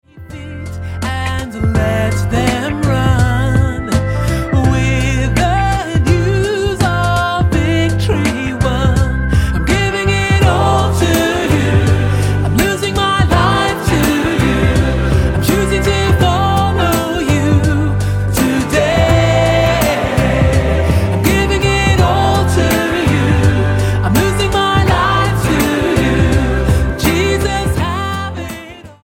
STYLE: Pop
treated to a R&B makeover complete with a new chorus